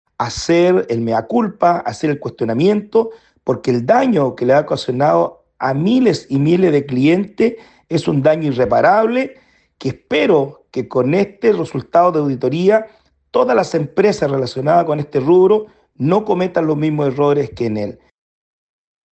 A raíz de estas diferencias, el diputado independiente pro Partido Por la Democracia, Cristián Tapia, señaló que Enel debe hacer un “mea culpa” por el daño causado.